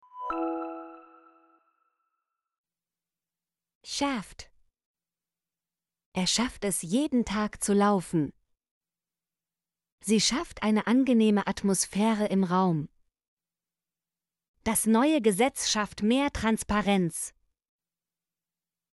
schafft - Example Sentences & Pronunciation, German Frequency List